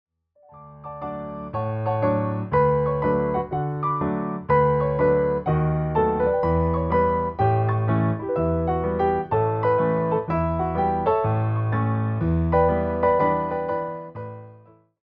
The performance favors clean voicing and balanced dynamics